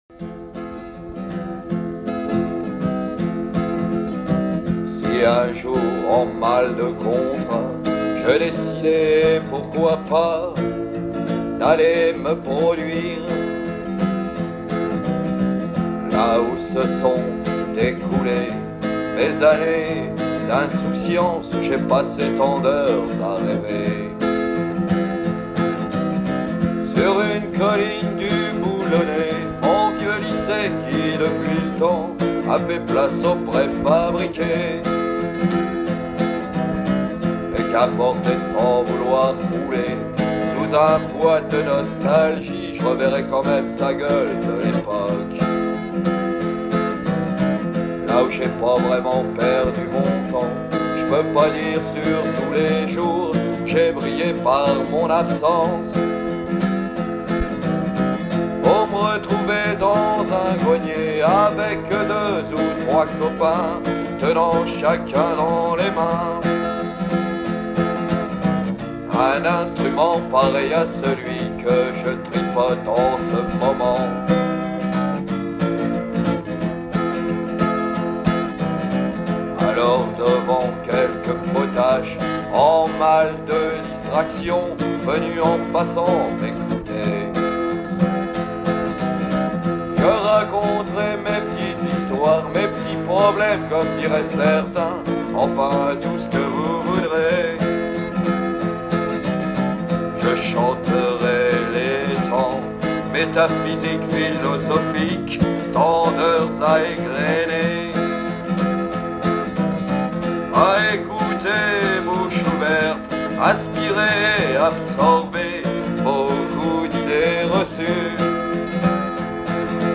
Je ne sais pas si dans quarante ans mes romans ne dateront pas trop, en tout cas, pour ce qui est de cette composition, force est de constater pour moi qu'elle sonne drôlement actuelle.